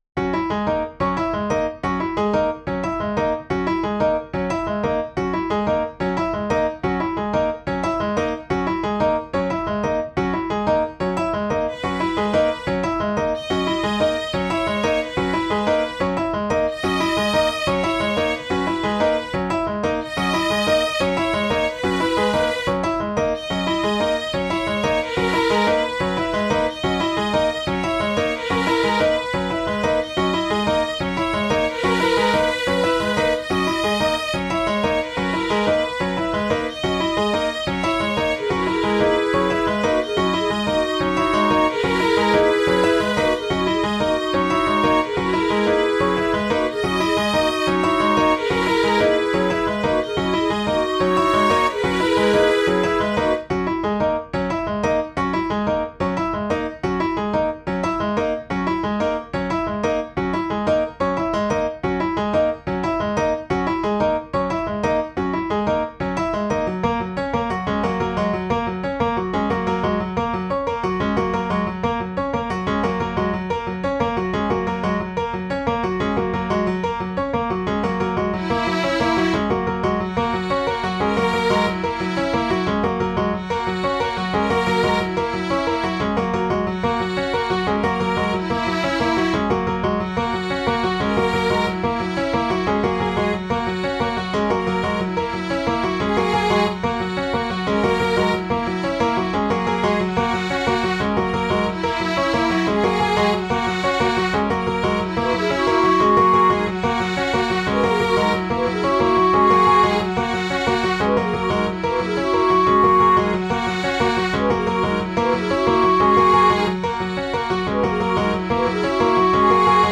And here is a mixdown of 3 more runs of my 5/4 "reichify" program. Very hypnotic...